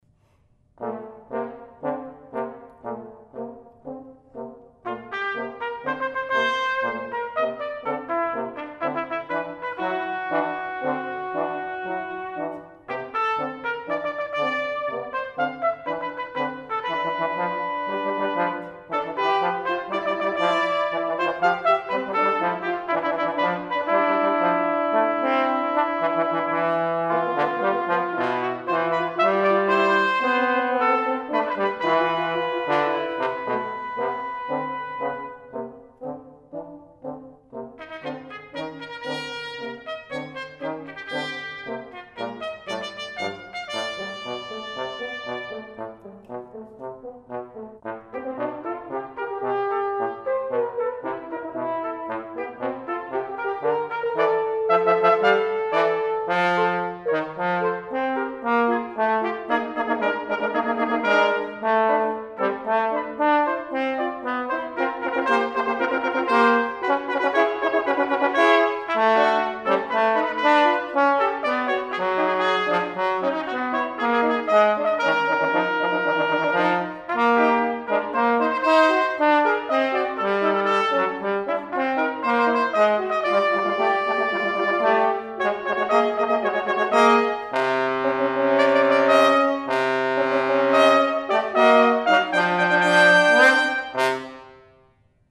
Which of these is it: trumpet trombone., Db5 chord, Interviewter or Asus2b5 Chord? trumpet trombone.